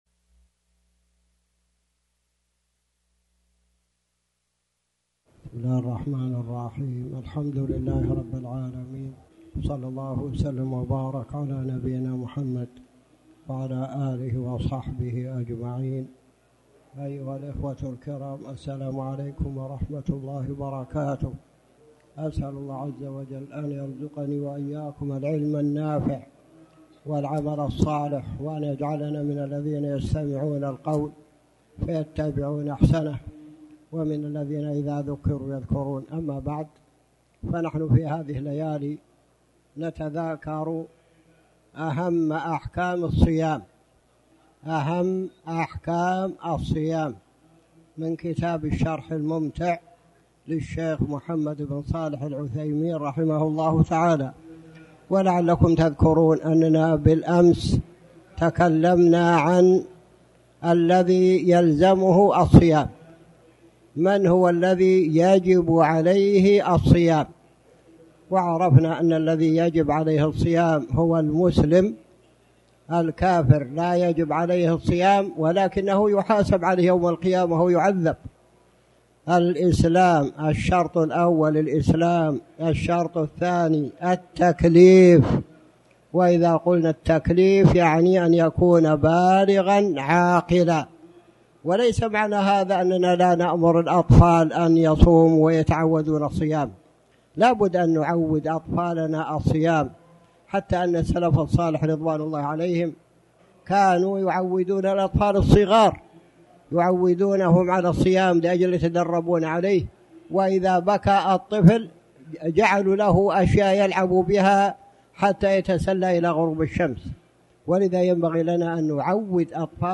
تاريخ النشر ٢٣ شعبان ١٤٣٩ هـ المكان: المسجد الحرام الشيخ